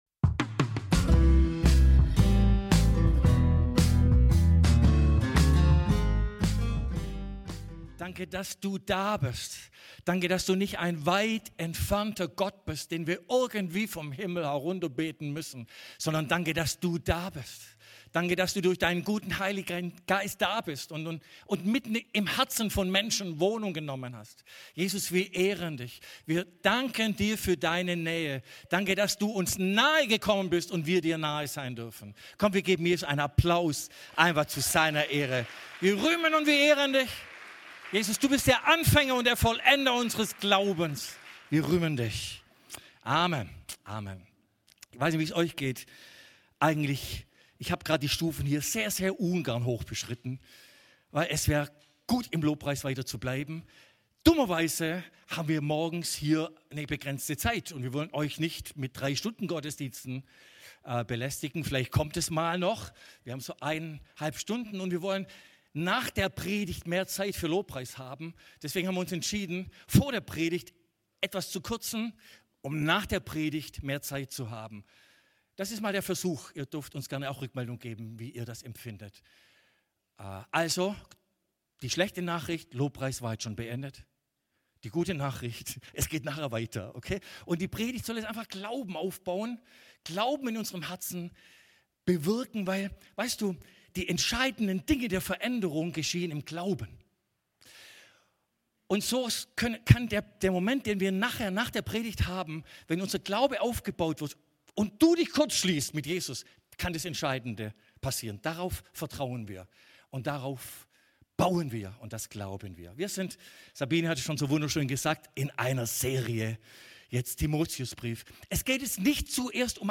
alle Predigten Umkämpftes Gut 5 Oktober, 2025 Serie: 1.